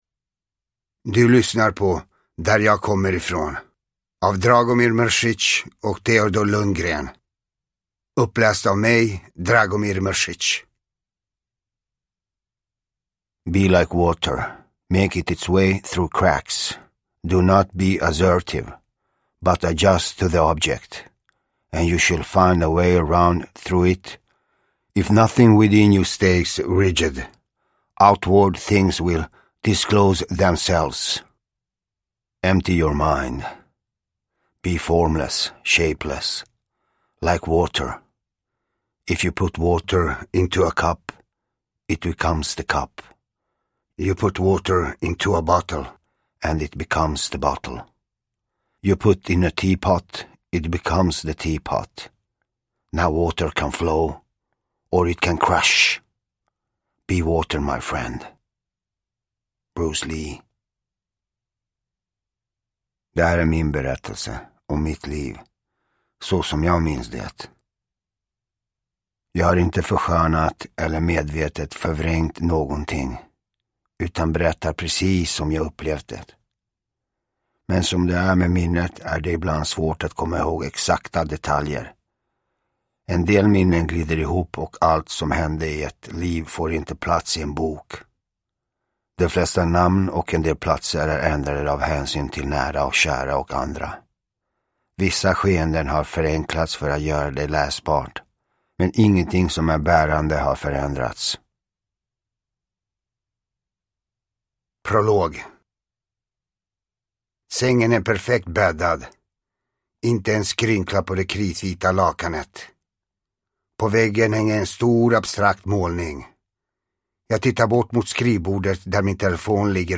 Uppläsare: Dragomir "Gago" Mrsic